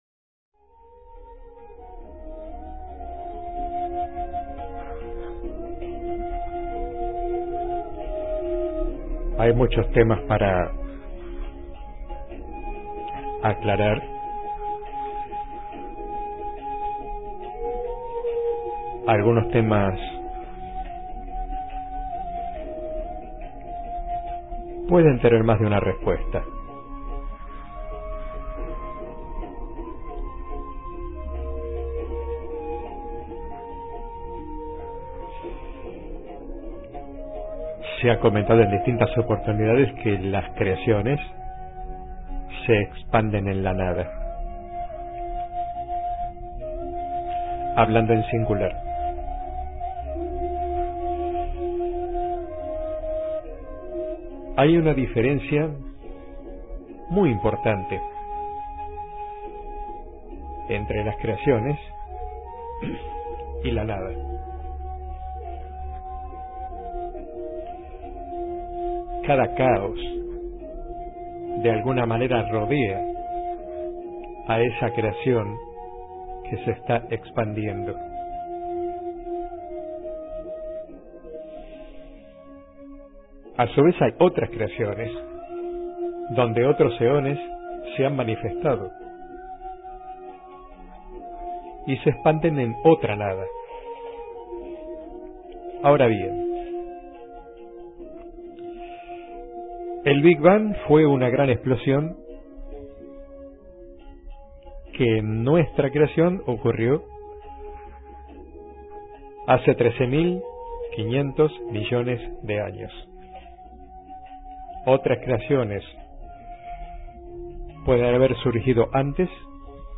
Entidad que se presentó a dialogar : Johnakan-Ur-El Se formulan preguntas acerca de varios temas en relación a Eón, su Creación y sus seres.